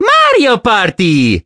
Mario exclaims the title of the game.
Mario_(Mario_Party!)_-_Mario_Party_6.oga